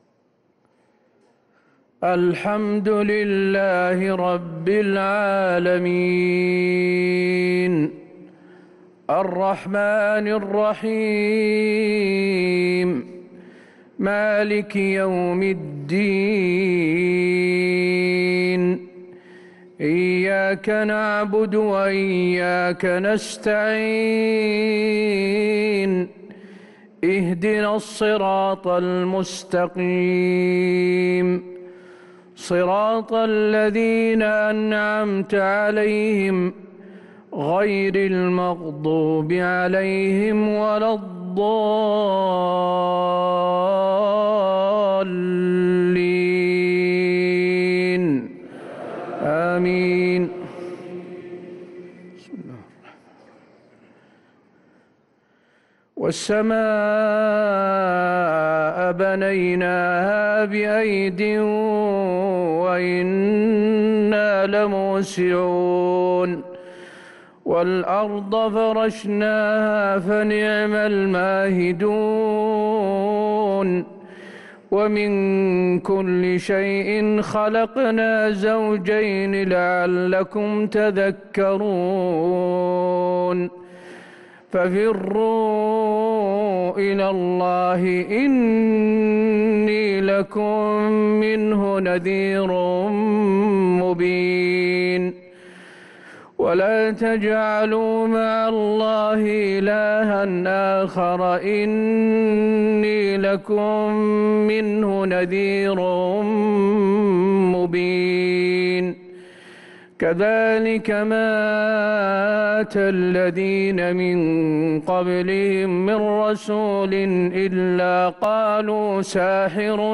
صلاة العشاء للقارئ حسين آل الشيخ 4 ذو الحجة 1444 هـ
تِلَاوَات الْحَرَمَيْن .